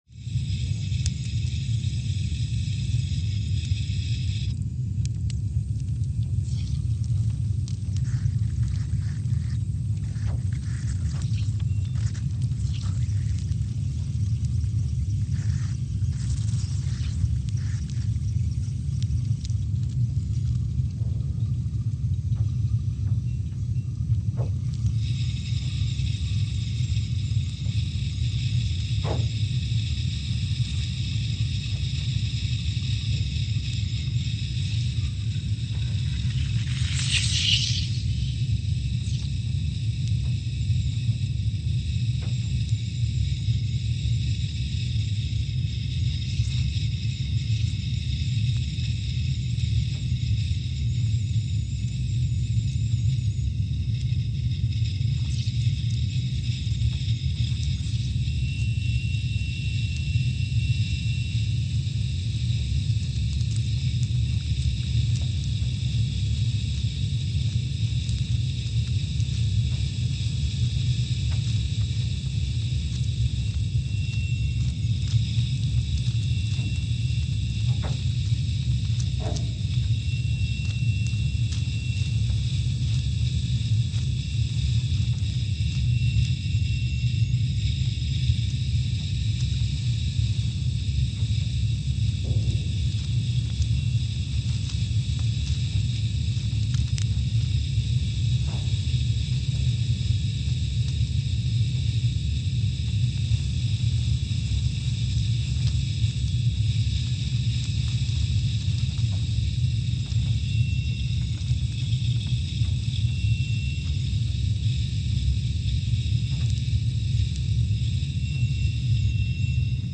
Scott Base, Antarctica (seismic) archived on June 2, 2021
Sensor : CMG3-T
Speedup : ×500 (transposed up about 9 octaves)
Loop duration (audio) : 05:45 (stereo)
SoX post-processing : highpass -2 90 highpass -2 90